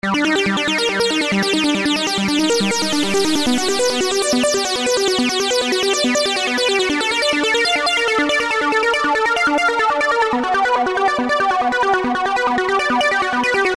滤波器合成器舞曲循环
描述：舞蹈循环，合成器循环
Tag: 240 bpm Dance Loops Synth Loops 2.31 MB wav Key : Unknown